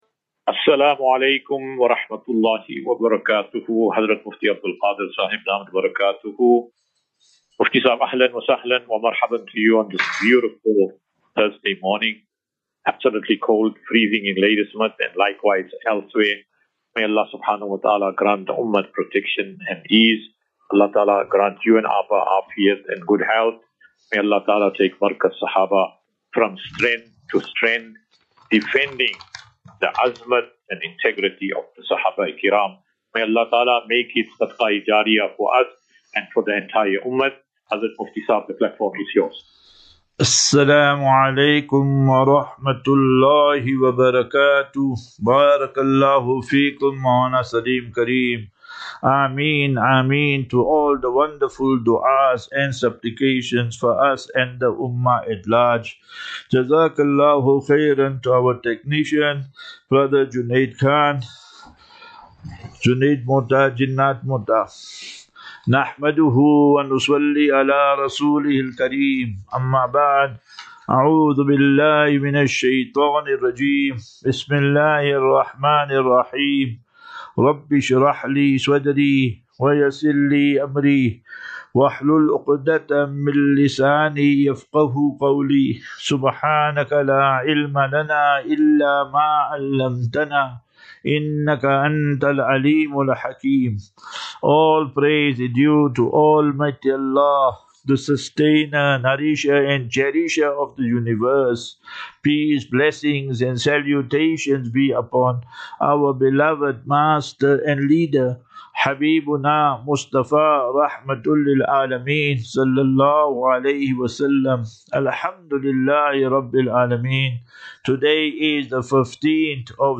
12 Jun 12 June 2025. Assafinatu - Illal - Jannah. QnA.